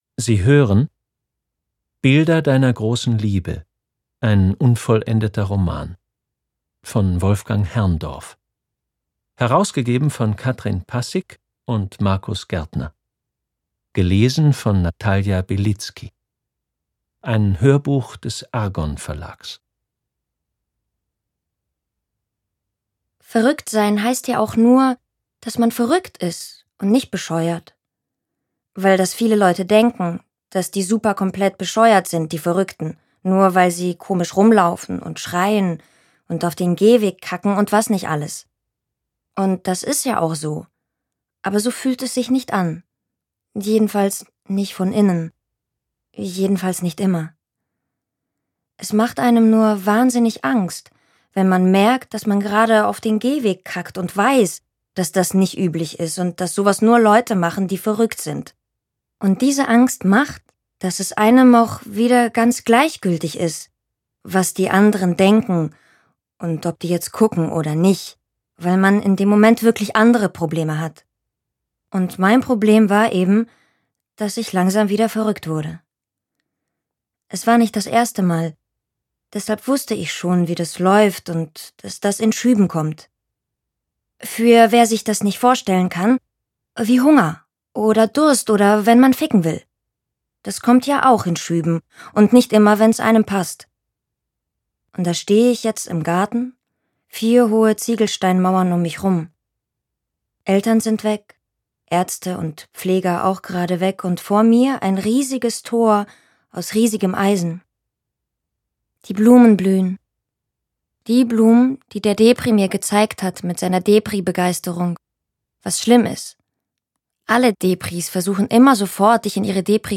Natalia Belitski (Sprecher)
Rau und ein wenig verletzlich, unverkennbar und sehr präsent – mit ihrer jungen Stimme lässt sie Hörbücher lebendig werden.